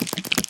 Звуки пауков
Все звуки оригинальные и взяты прямиком из игры.
Передвижение/Шаги №4
SpiderStep4.mp3